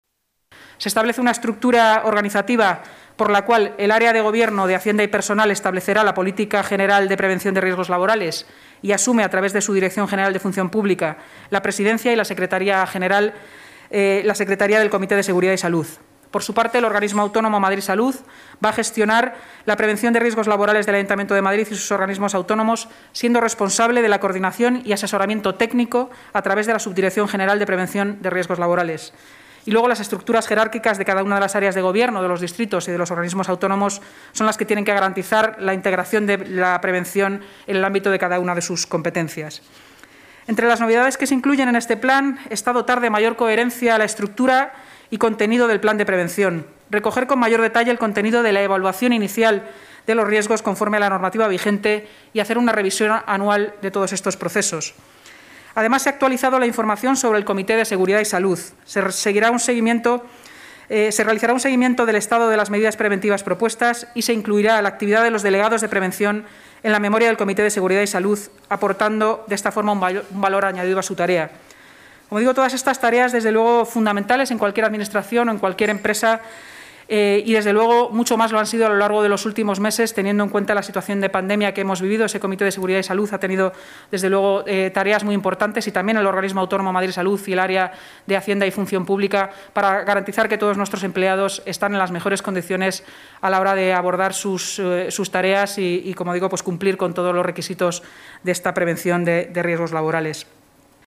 Nueva ventana:Inmaculada Sanz, portavoz del Gobierno municipal